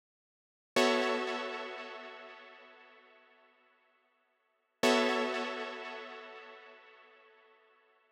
12 Synth PT1.wav